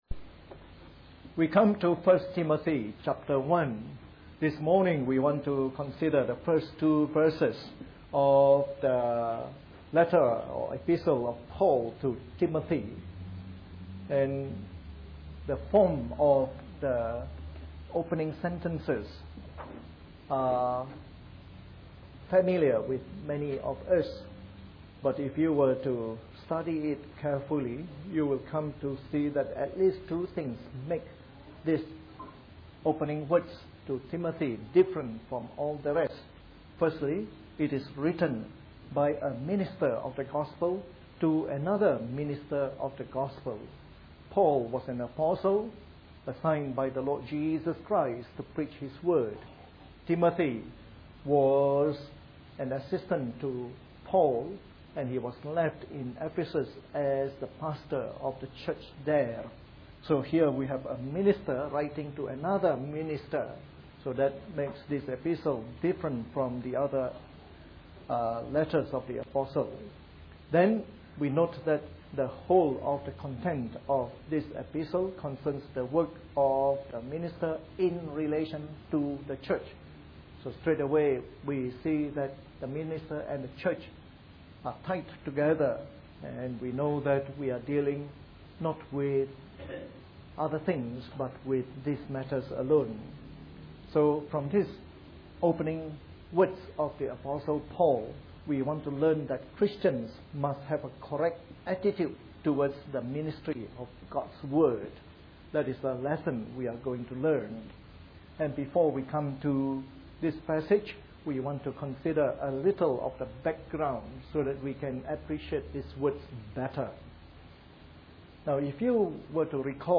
A sermon in the morning service from our new series on 1 Timothy.